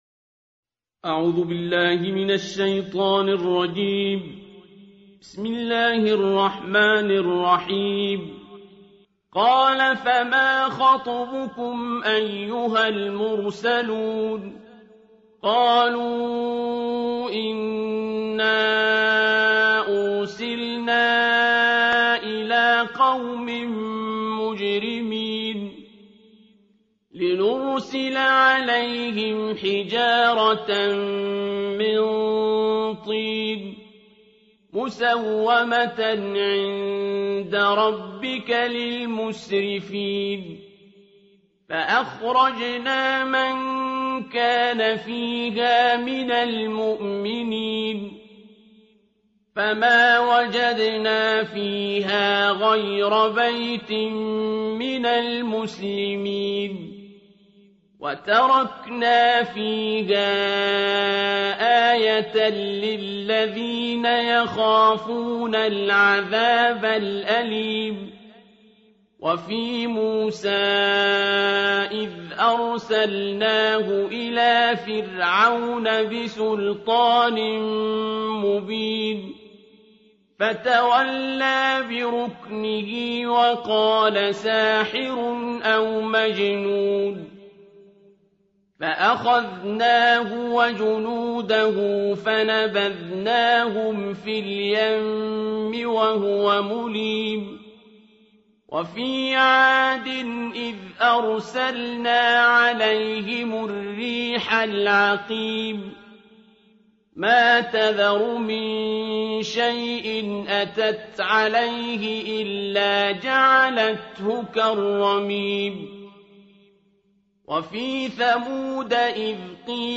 صوت/ ترتیل جزء بیست‌وهفتم قرآن توسط"عبدالباسط"